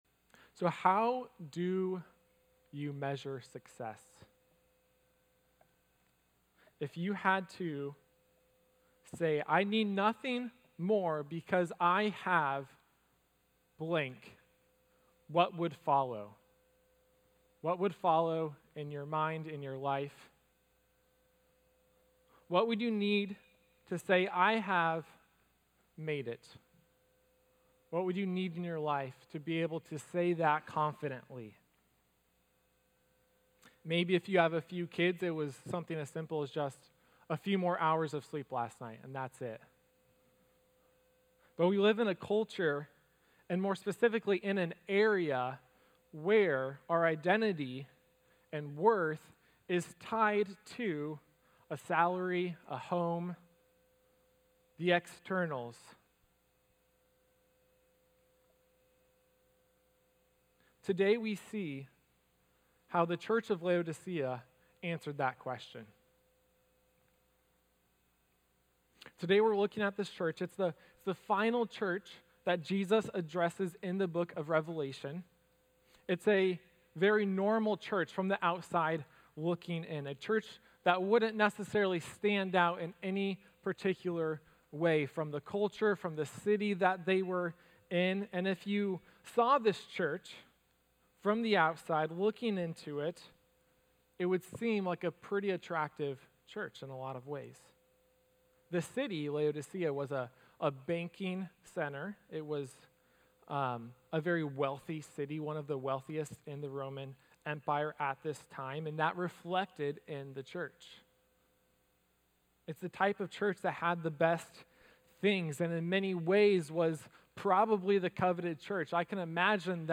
Sermon-Audio-3.17.24.mp3